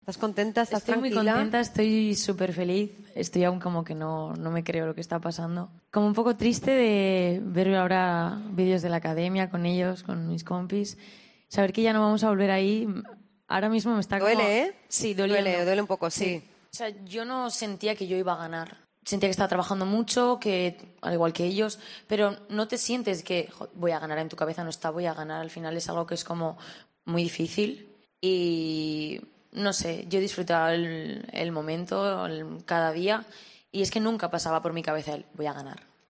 La zaragozana Naiara, se muestra feliz y contenta tras ganar la última edición de Operación Triunfo